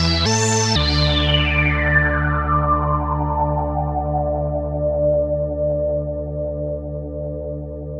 Synth 38.wav